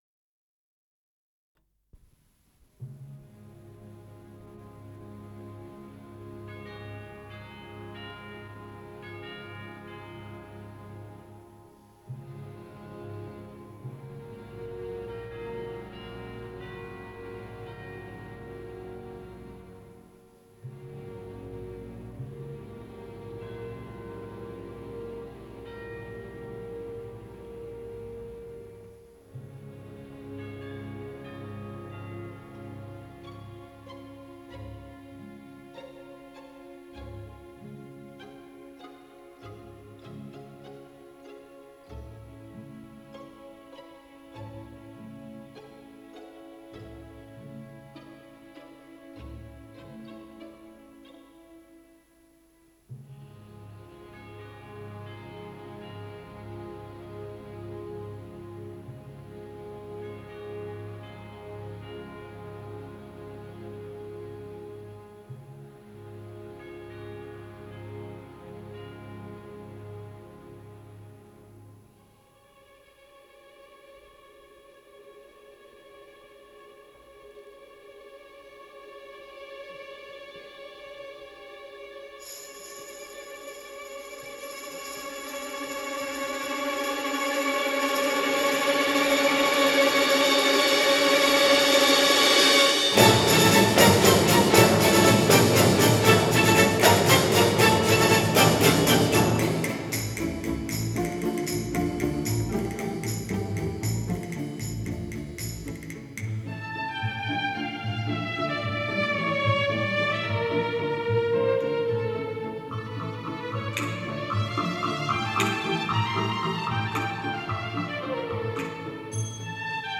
Исполнитель: Государственный камерный оркестр